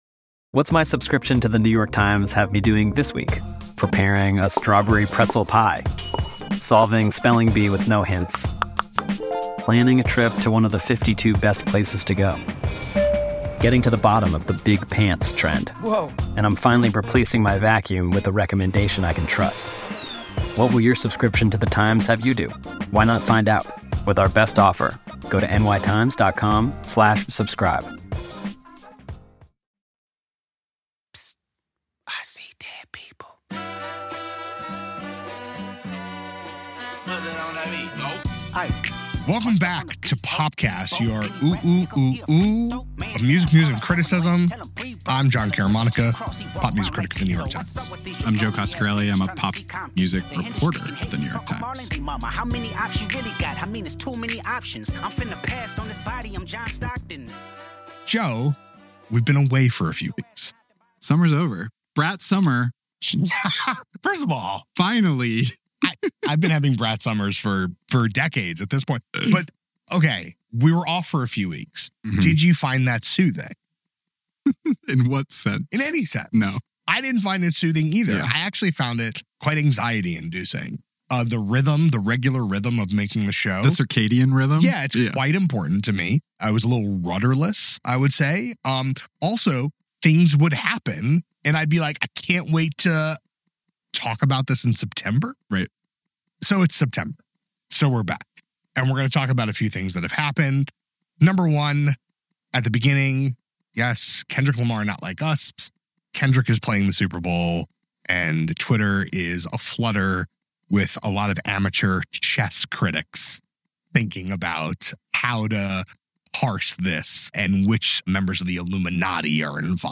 Plus: A conversation about the career of Rich Homie Quan, who died last week.